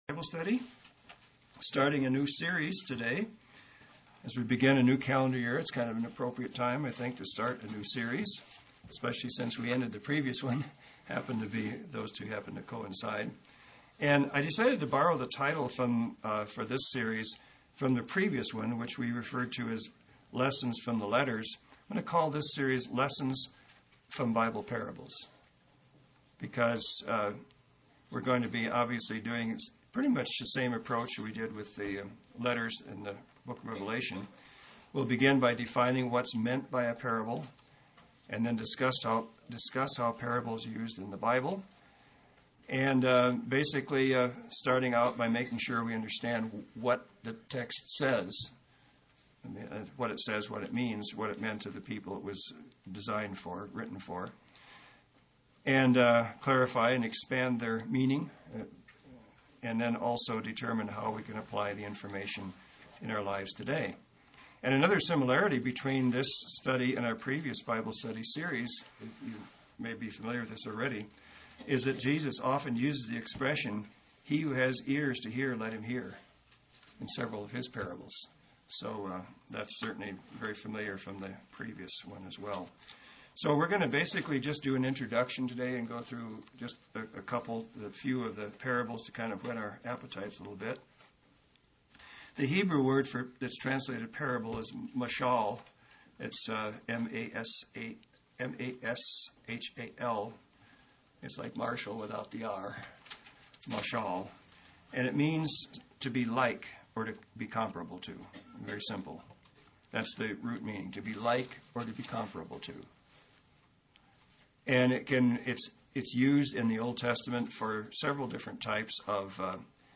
This is the first in a Bible study series expounding parables of the Bible: what they say, what they meant to those to whom they were directed, and we can learn from them and apply today.